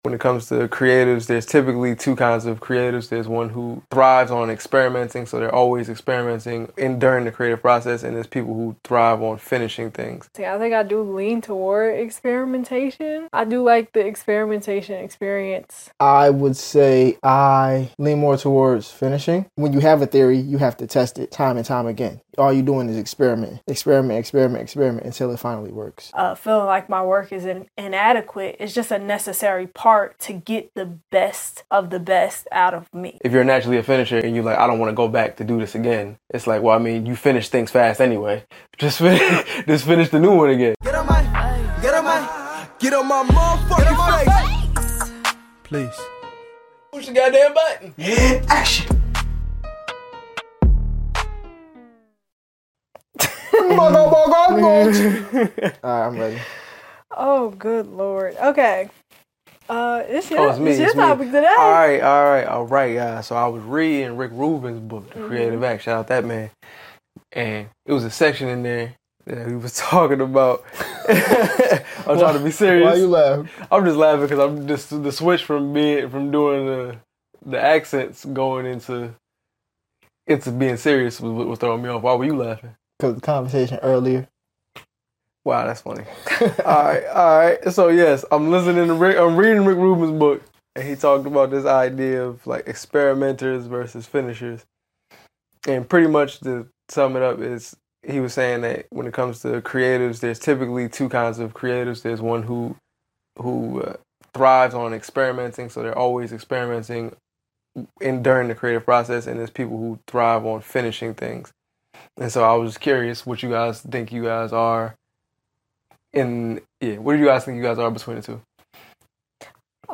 This conversation is raw, funny, and deeply relatable for anyone navigating the creative process.